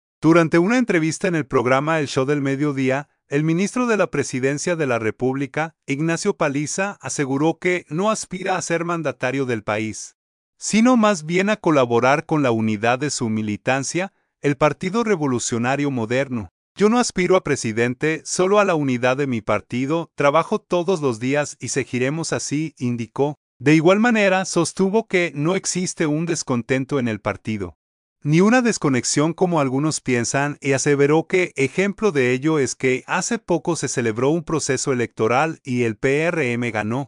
Durante una entrevista en el programa el Show del medio día, el ministro de la presidencia de la República, Ignacio Paliza, aseguró que no aspira a ser mandatario del país, sino más bien a colaborar con la unidad de su militancia, el partido Revolucionario Moderno.